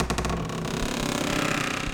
door_A_creak_11.wav